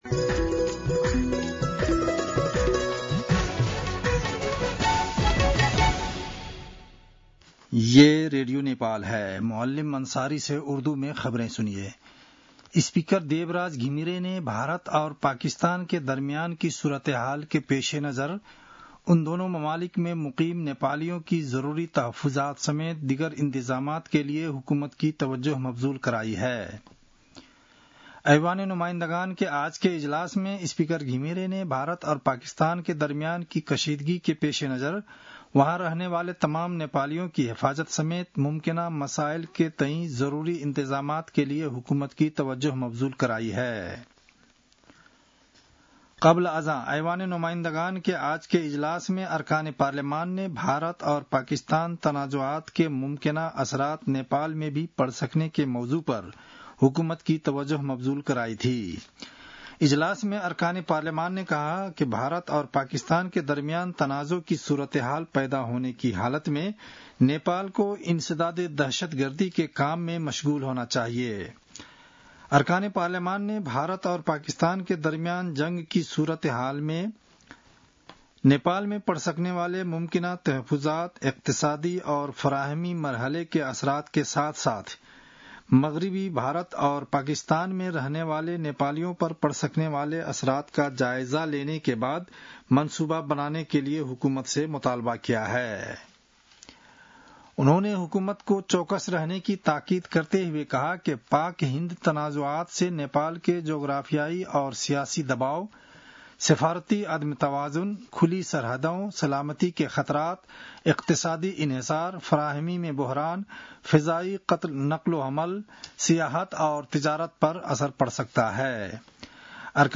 उर्दु भाषामा समाचार : २६ वैशाख , २०८२
Urdu-NEWS-1-27.mp3